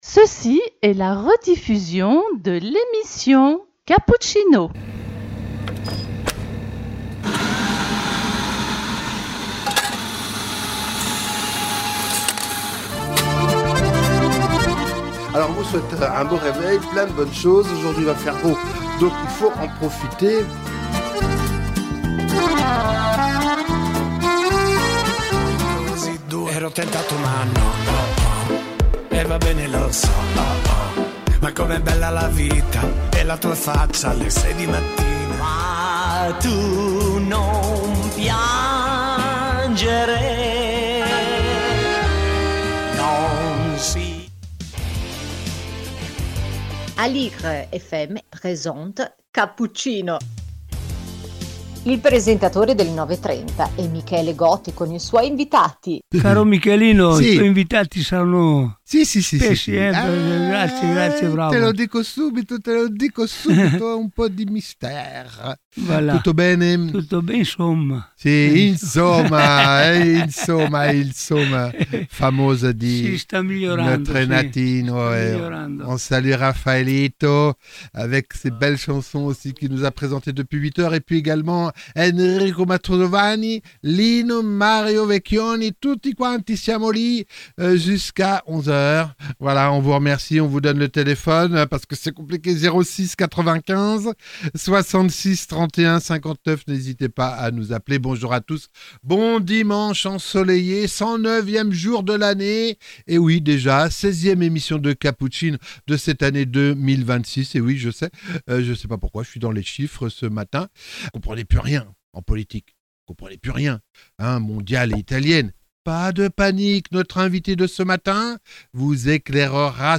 Cappuccino # 19 avril 2026 - invité Alberto Toscano, journaliste et politologue